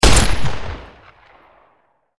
wpn_10mmpistolfire_3d_01.wav